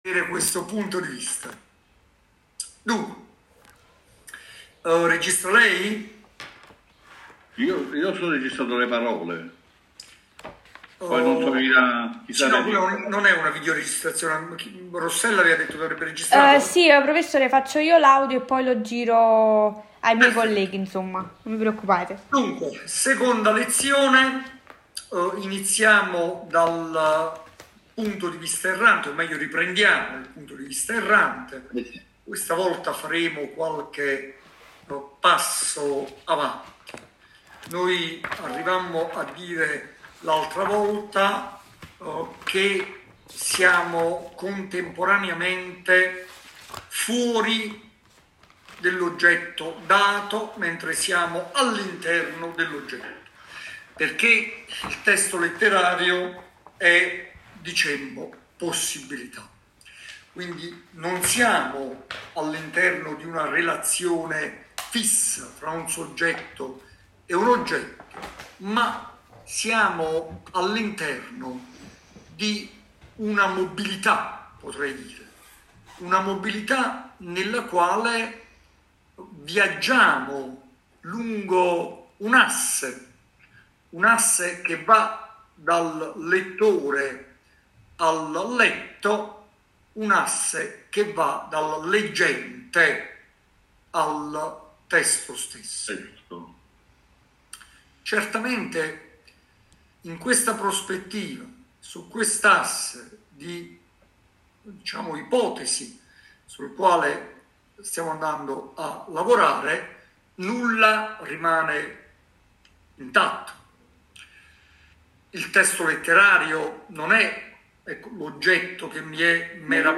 Lezione Filosofia Teoretica 1-4-2023